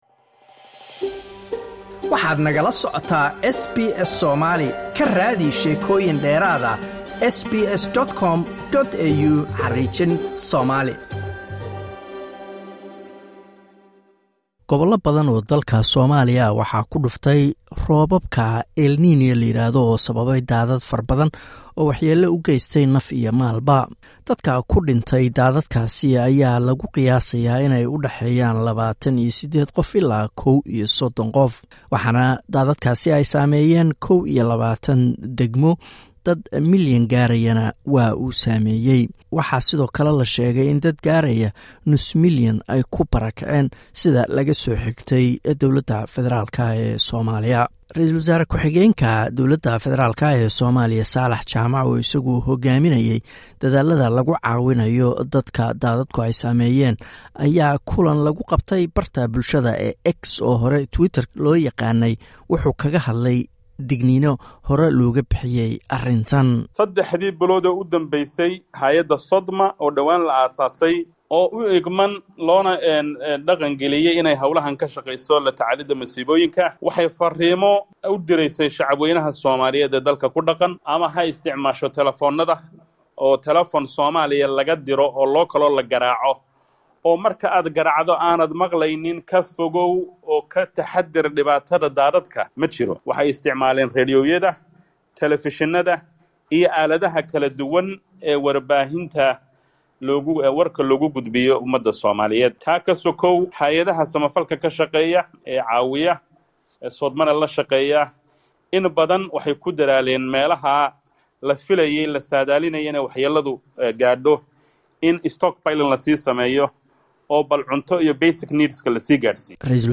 Warbixin daadadka ka dhacay Soomaaliya